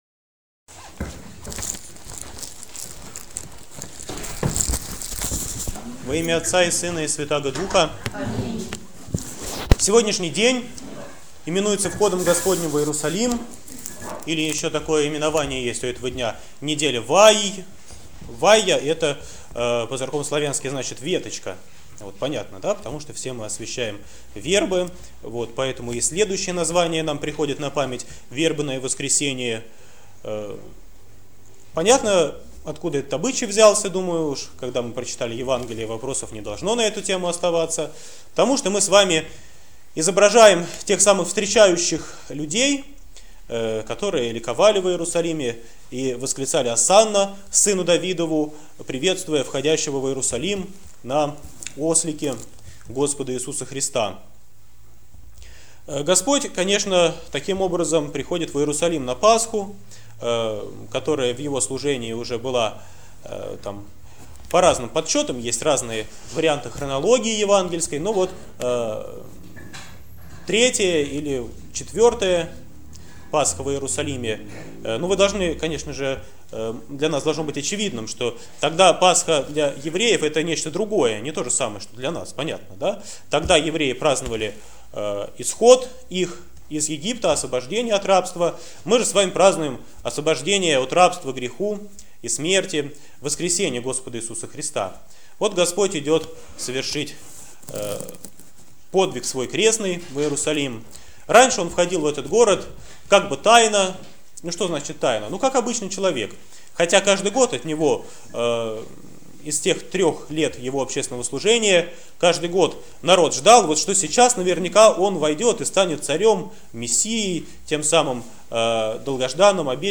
Проповедь на Вербное воскресение 2014